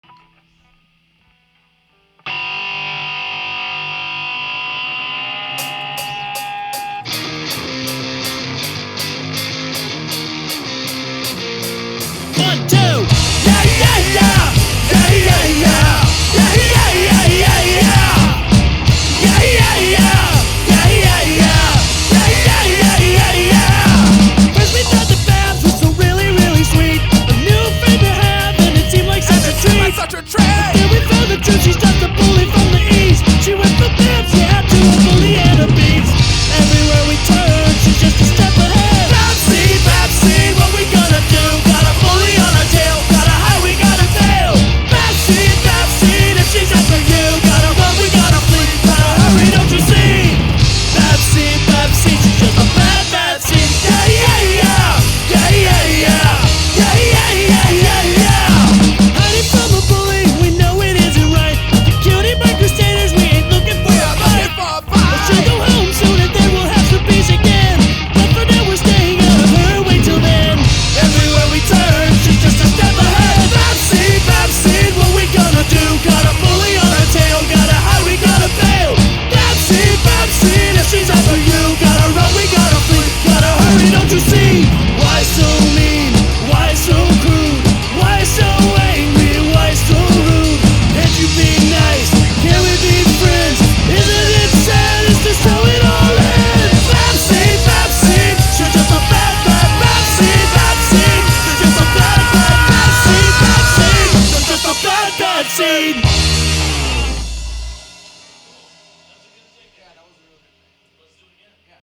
Hardcore Punk Cover